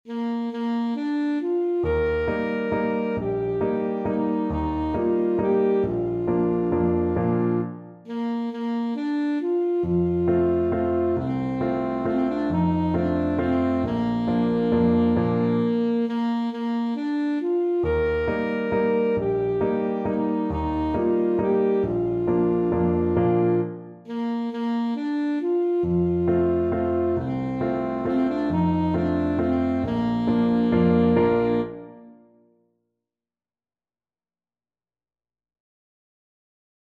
Alto Saxophone version
Alto Saxophone
3/4 (View more 3/4 Music)
One in a bar .=c.45